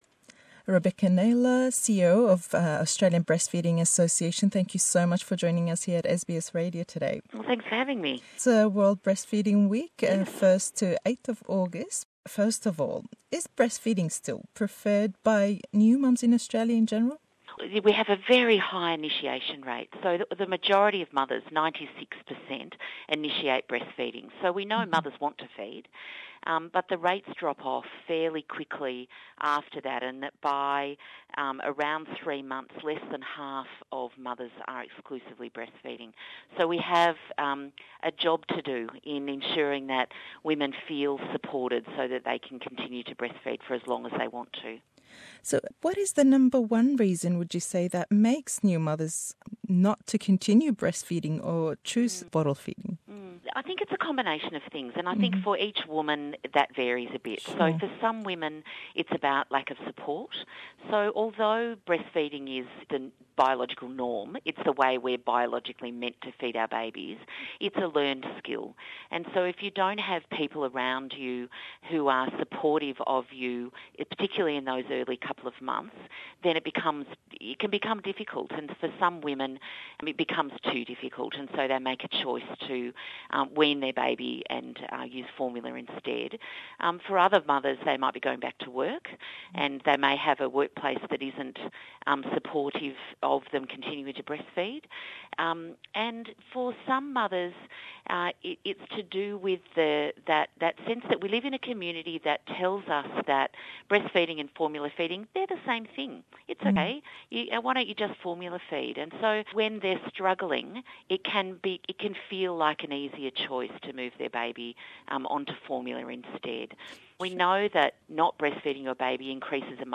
em hevpeyvîn e-man ancam da, bo tawûtwê kirdinî sûdekanî shîrdnî dayk bo mindallî sawa, û dayk-îsh. Herweha, boçûnî komellgayî Australî beramber be ew daykaney shîrî xoyan deden be mindalle sawakaniyan le cêye gishtêkan, ke dijayetî dekrêt le layen beshêkî komellgawe.